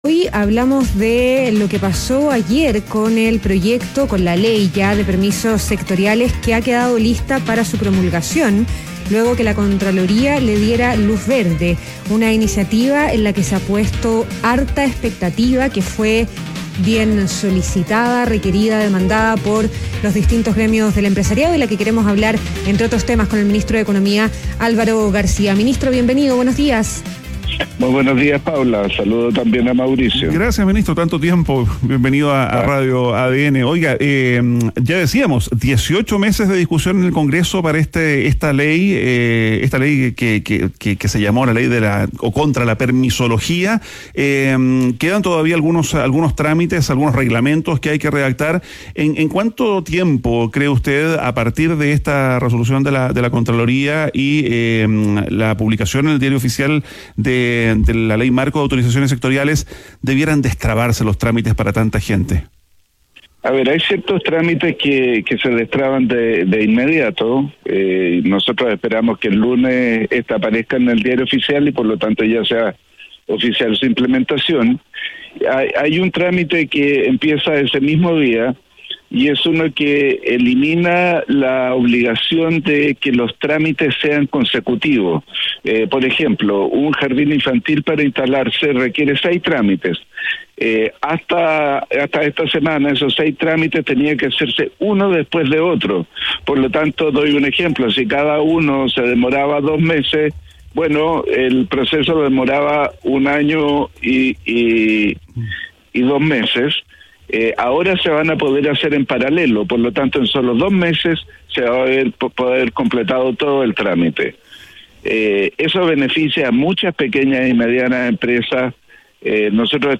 Entrevista a Álvaro García, ministro de Economía - ADN Hoy